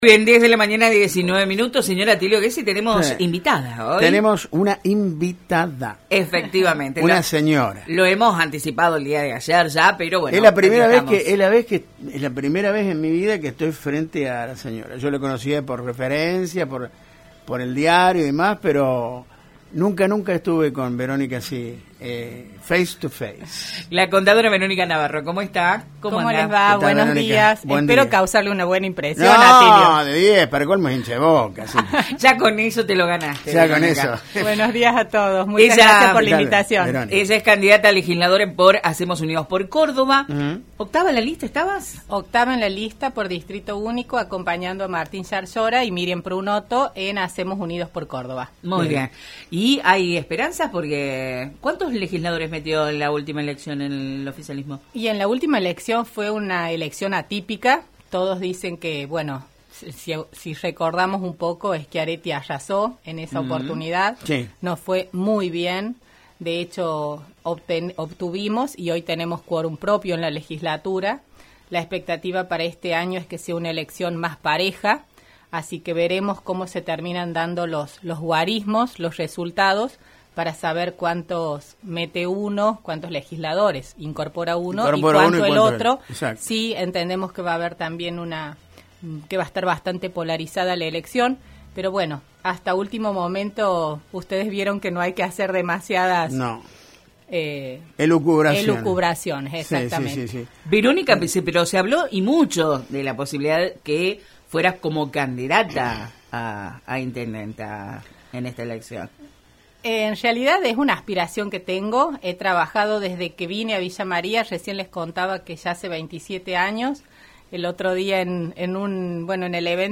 En la entrevista aprovechamos para conocer su eje de propuestas en lo que sería su gestión como legisladora si es que accede a una banca.